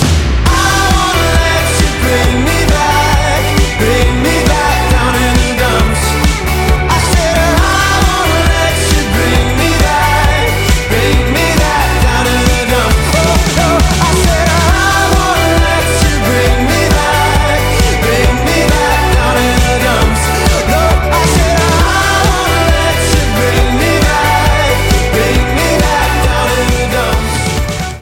• Качество: 192, Stereo
Pop Rock